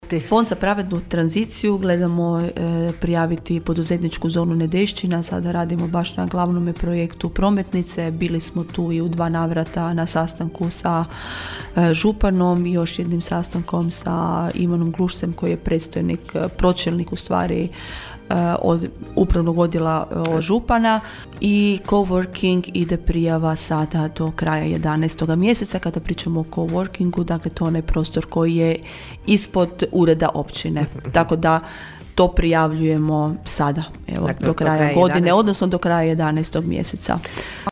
Realizacija projekta coworkinga trebala bi, ističe općinska načelnica Irene Franković, početi iduće godine.